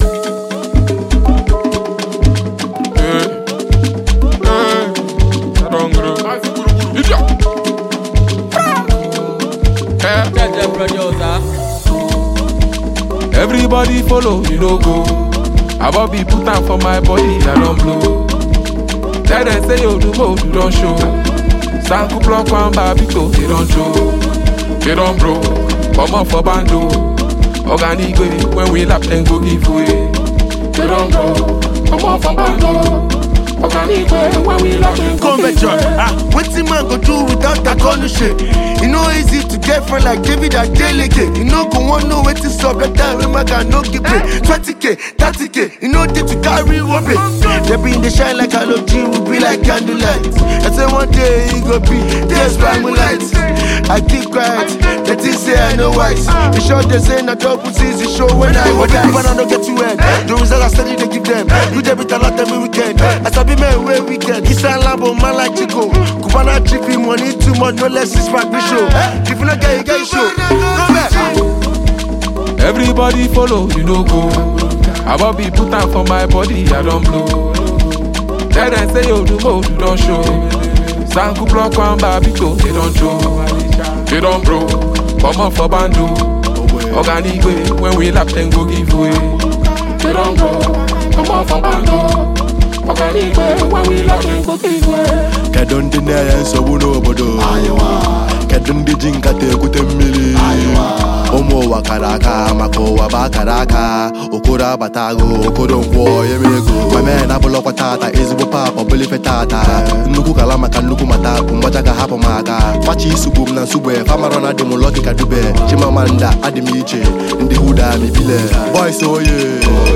hip-hop-infused record